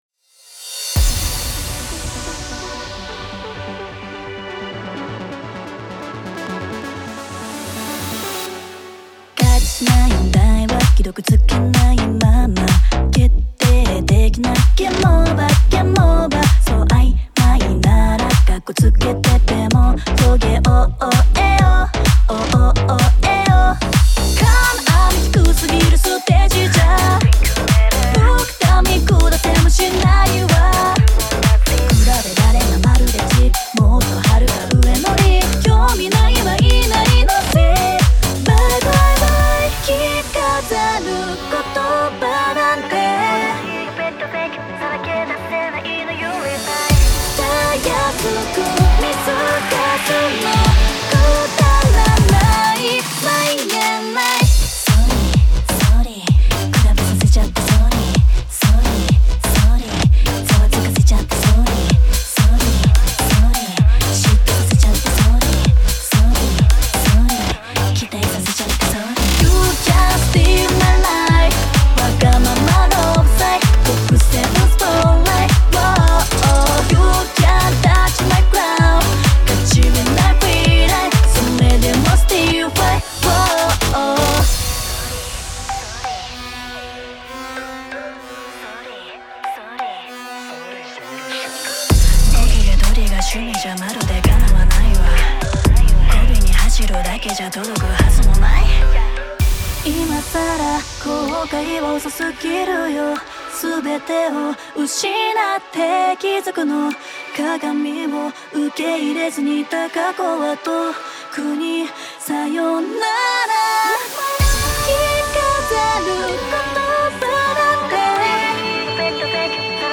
現在、冒頭のベース制作セクションを無償公開しています。